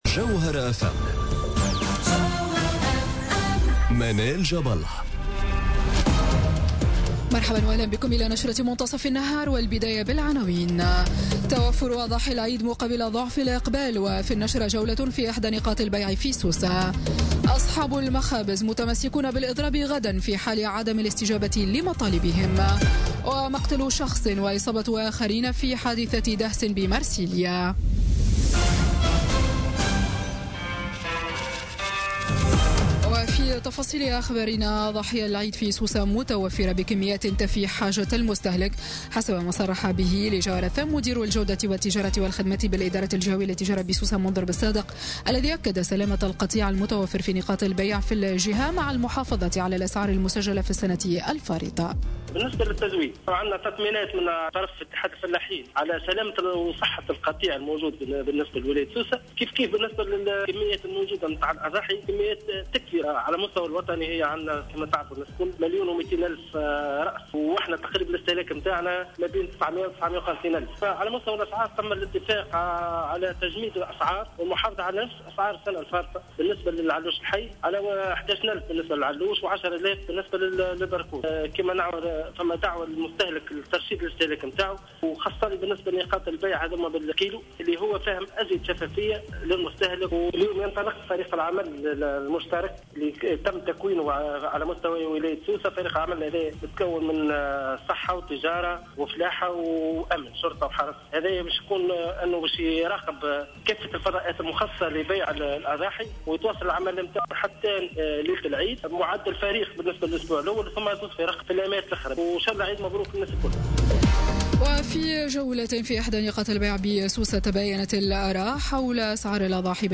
نشرة أخبار منتصف النهار ليوم الإثنين 21 أوت 2017